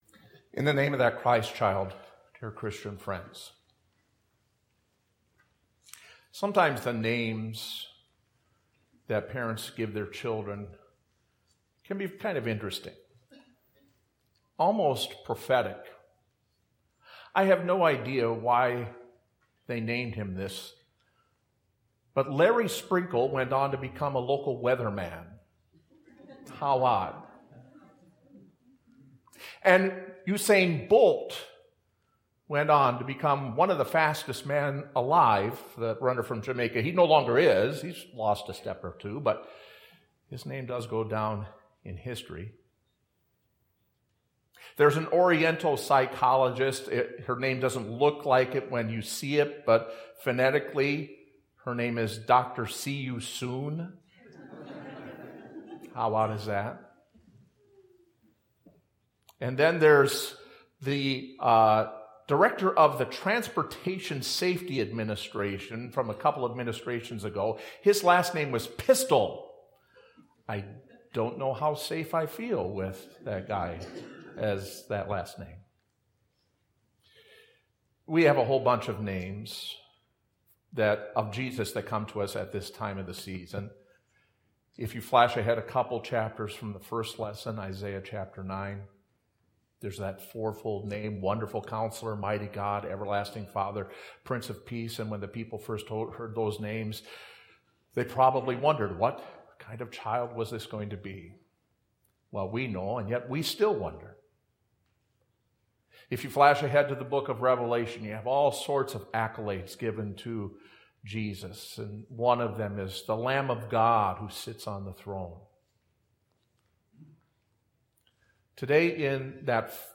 Services (the most recent service is in the first box)